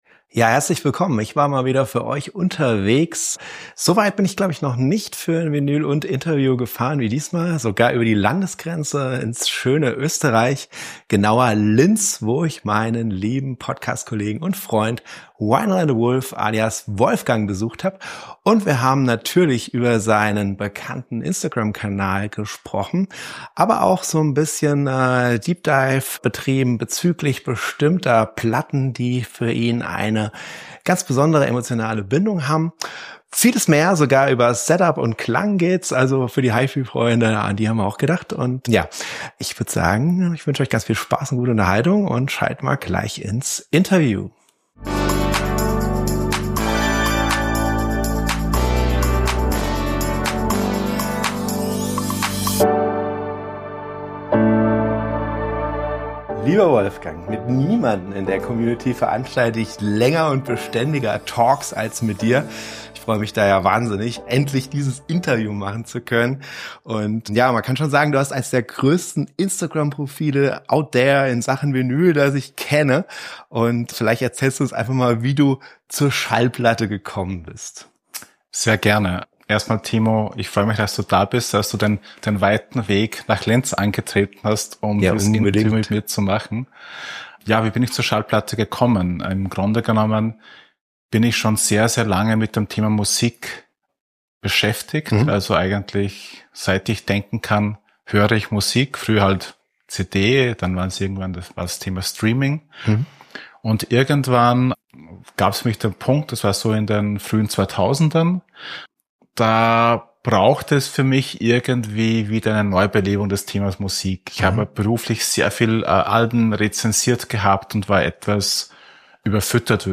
Ein sehr kurzweiliger Talk unter Freunden und Vinylenthusiasten aus der schönen Stadt Linz in Österreich...